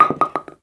bowlingPinFall_r_4.wav